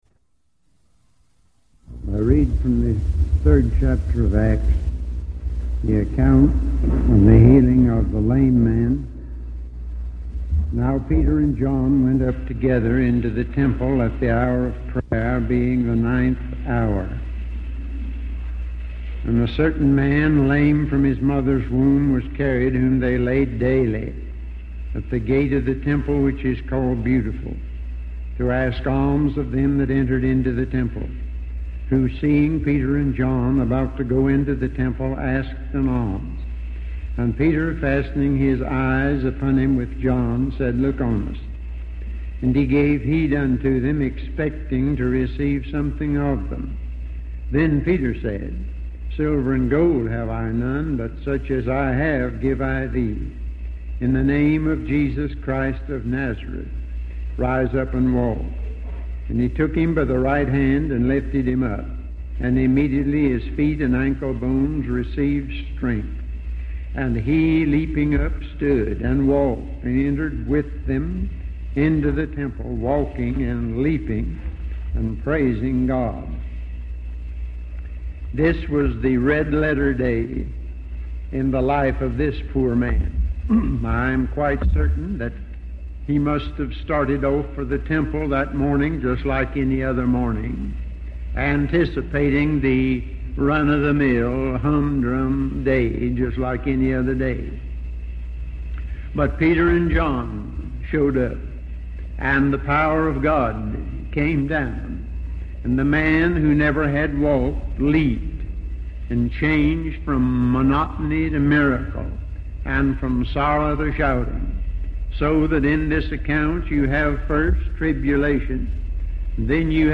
In this sermon, the preacher discusses the challenges that people face as they get older and how they can become stagnant in their Christian life. He emphasizes the importance of preaching to older people just as much as to young people.